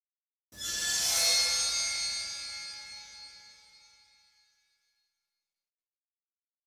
Suspense 3 - Stinger 3.wav